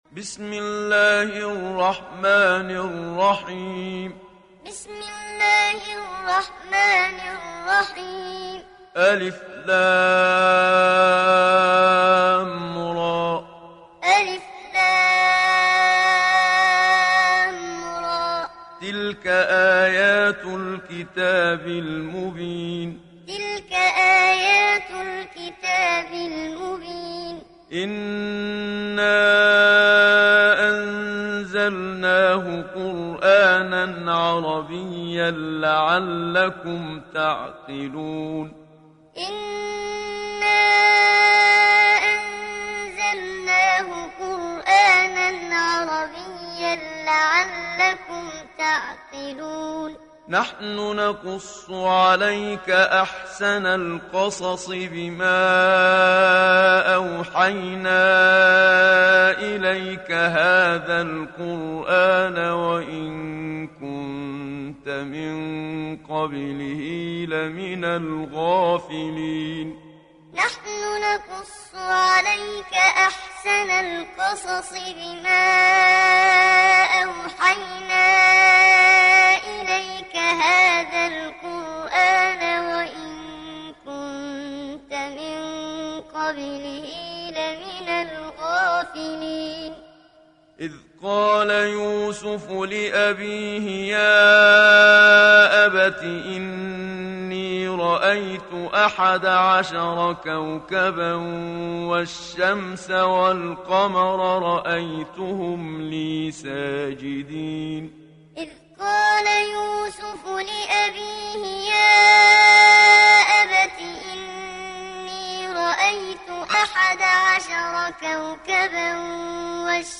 تحميل سورة يوسف mp3 بصوت محمد صديق المنشاوي معلم برواية حفص عن عاصم, تحميل استماع القرآن الكريم على الجوال mp3 كاملا بروابط مباشرة وسريعة
تحميل سورة يوسف محمد صديق المنشاوي معلم